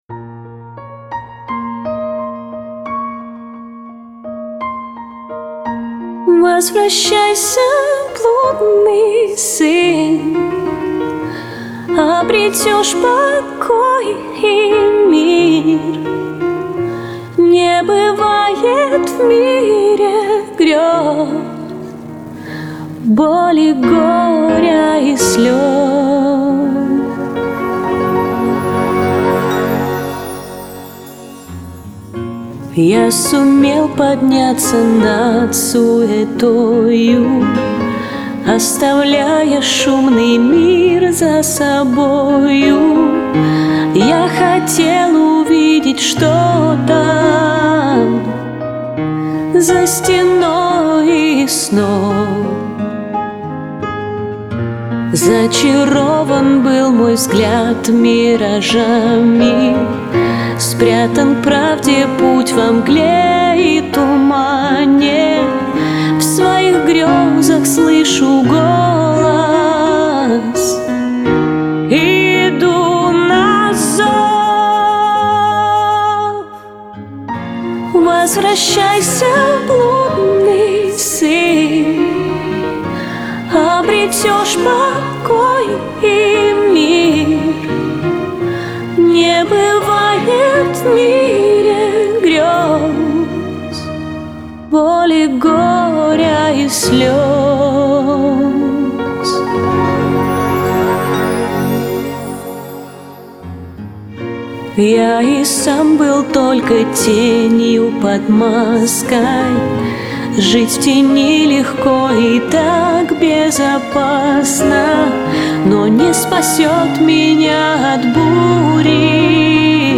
русскую версию песни